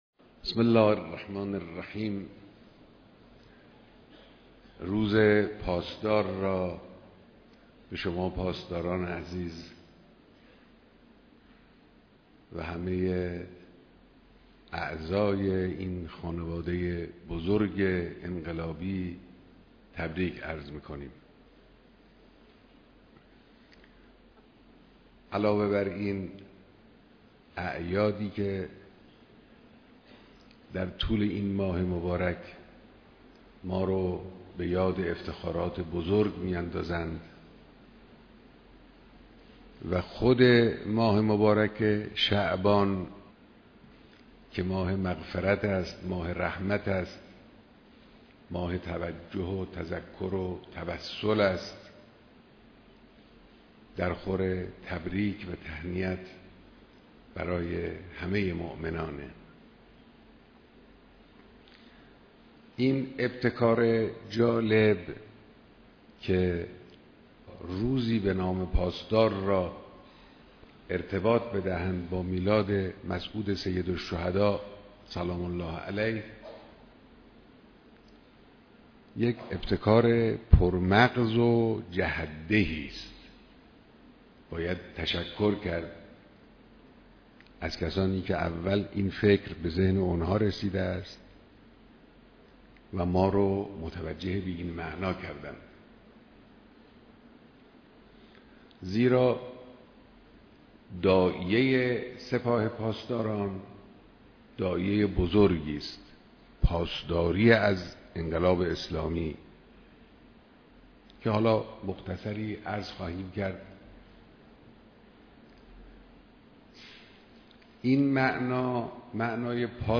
بیانات در دیدار فرماندهان سپاه پاسداران